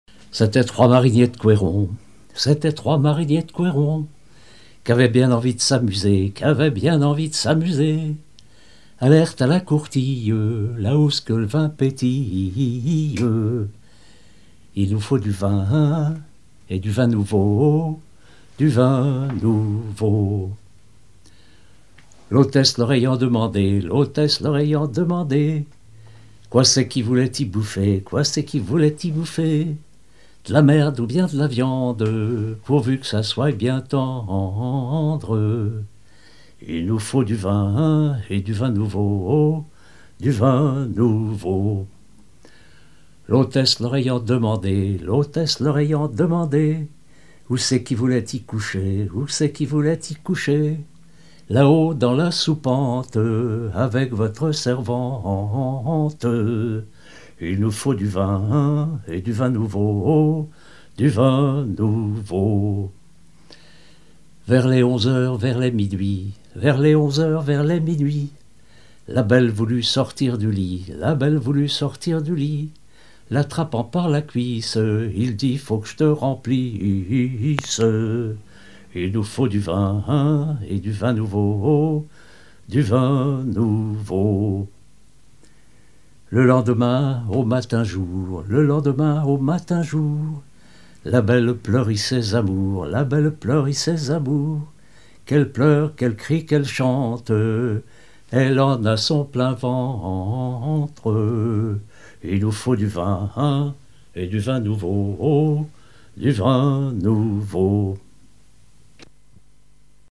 chansons maritimes et paillardes
Pièce musicale inédite